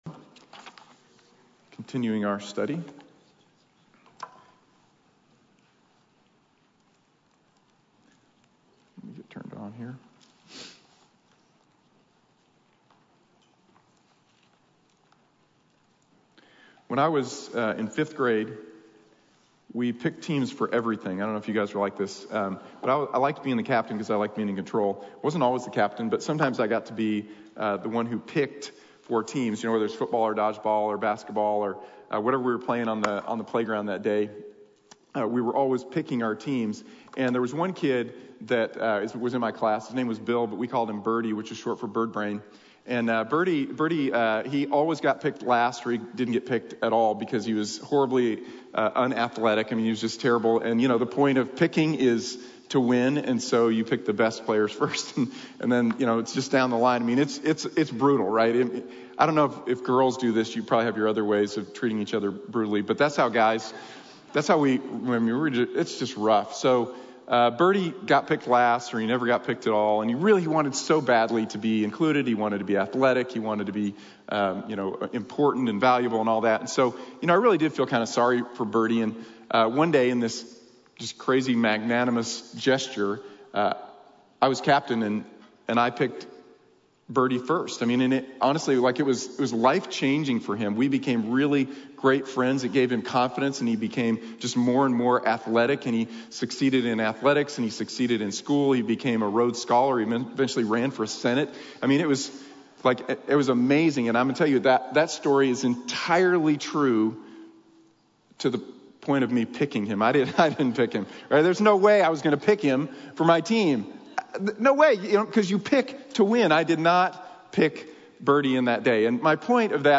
Proving the Gospel | Sermon | Grace Bible Church